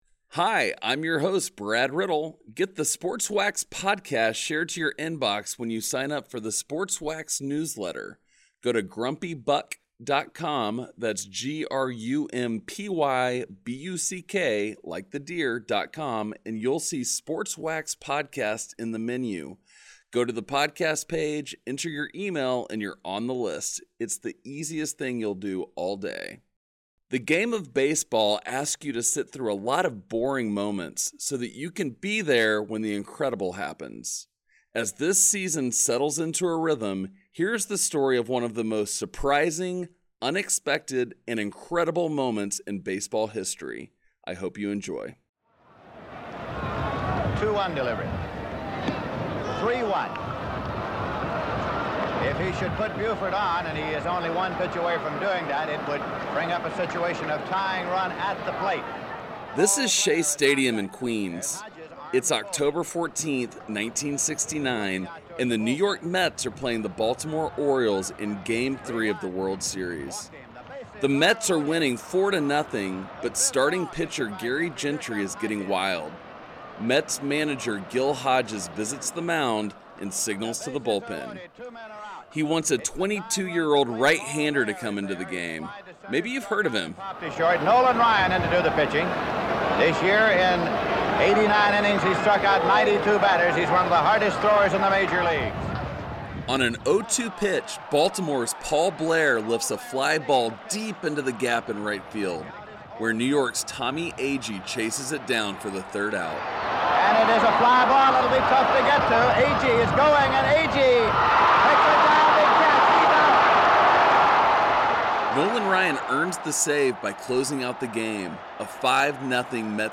Sports Wax is a weekly documentary that explores sports stories from yesteryear — memories of players, coaches, and teams that takes us back in time.